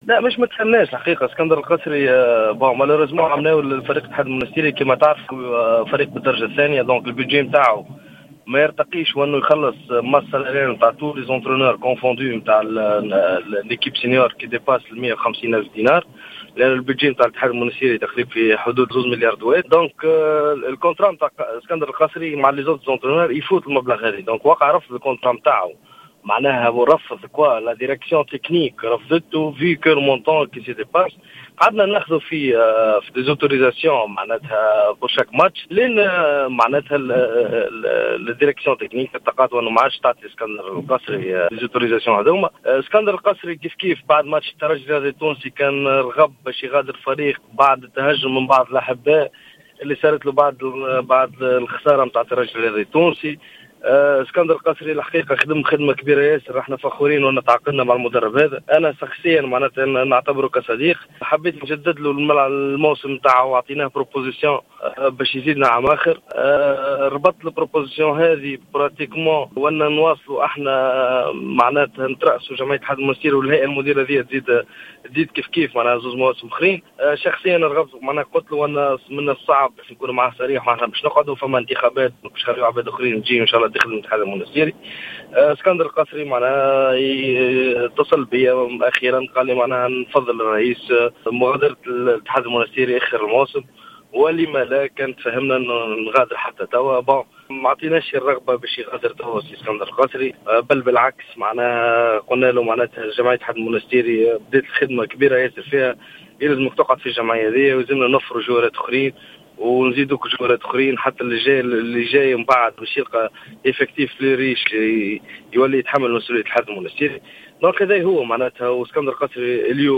تصريح خاص بإذاعة الجوهرة أف أم